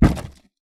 Punching Box Intense D.wav